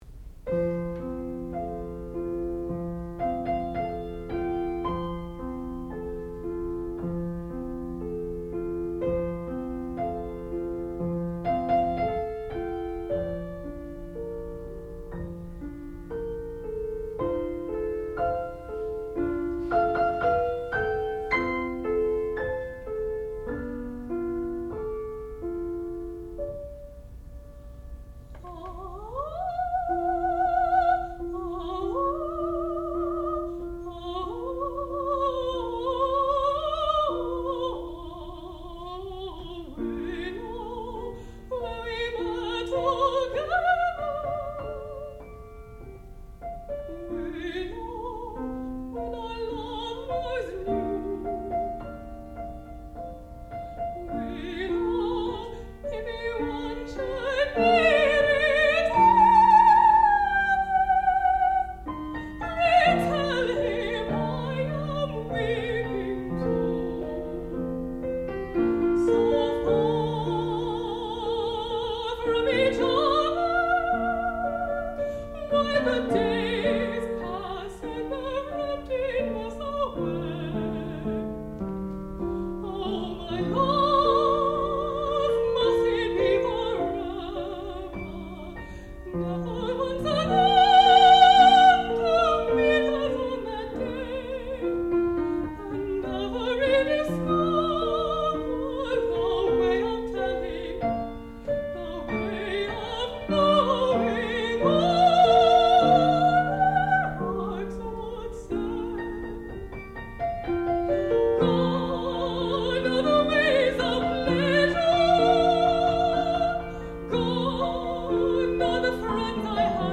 sound recording-musical
classical music
Qualifying Recital
soprano